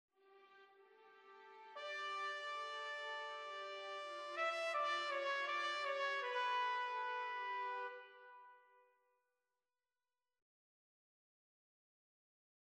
Theme # 2: If you’ve listened to Chapter 2‘s excerpt, you know that the trumpet heralds a new idea — not one that stands on its own, but one that is a slower, slightly modified version of the main idea starting the second thematic area (#3 below.)
theme2_trumpet.mp3